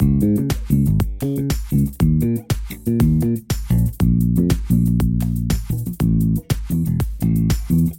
لوپ 6/8 گیتار بیس 2 دانلود سمپل گیتار بیس 6/8 مارکتی
لوپ 6/8 گیتار بیس 2 کامل ترین سمپل پک گیتار بیس با کیفیت فوق العاده بالا مخصوص آهنگ های مارکتی و بستکی 6/8 در پترن ها و فیگور های ریتمی جذاب
demo-bass-guitar-vol2.mp3